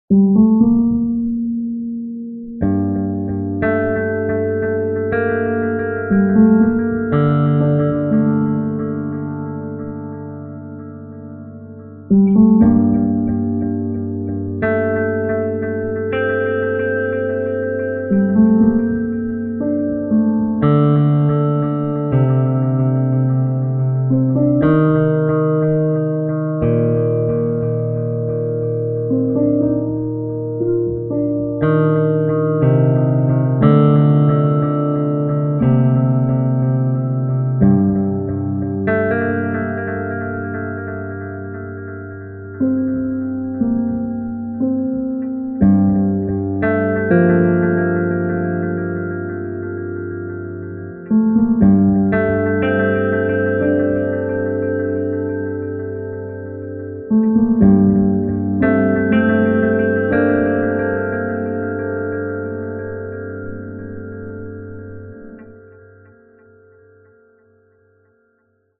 Instrumente - Piano, Electric Guitar Tempo - Medium BPM - 89